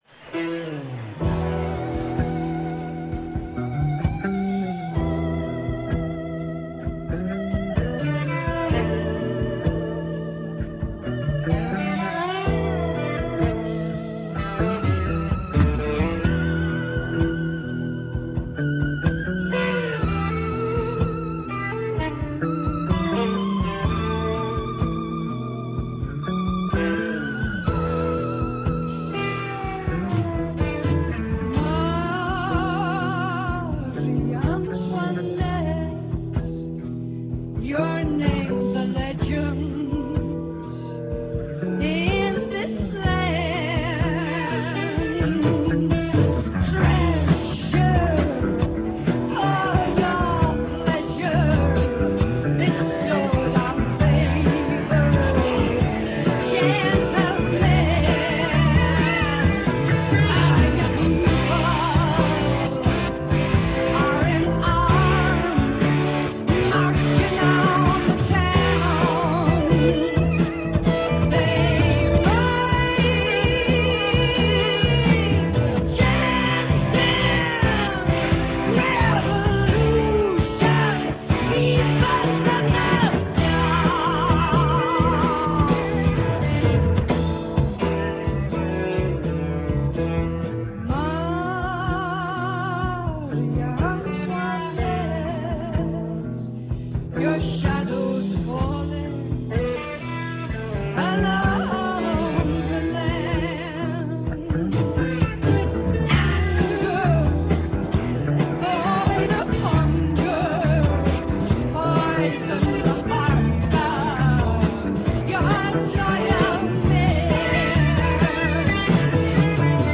stereo, 4.0 Khz, 20 Kbps,, file size: 417 Kb